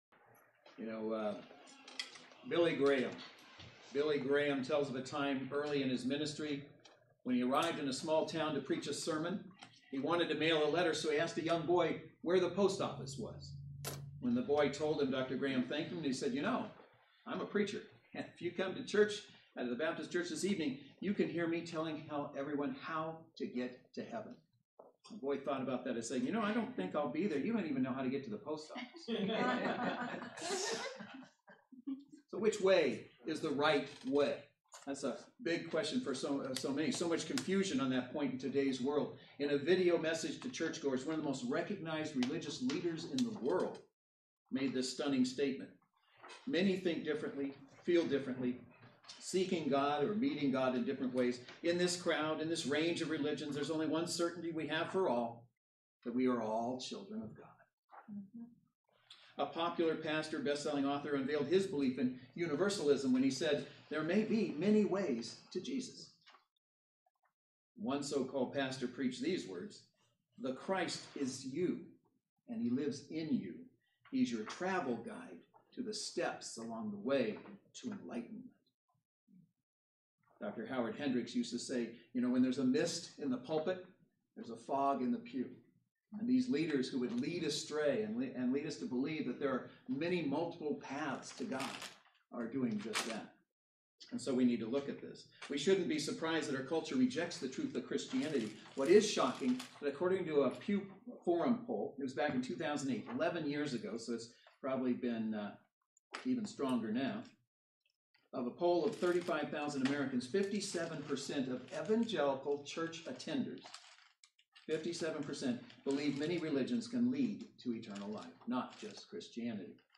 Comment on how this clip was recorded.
John 14:6 Service Type: Saturday Worship Service Bible Text